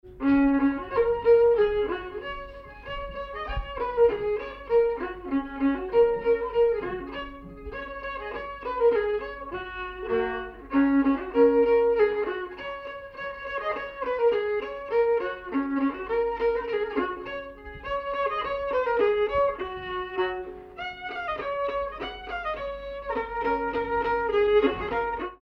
Scottisch
circonstance : bal, dancerie
Pièce musicale inédite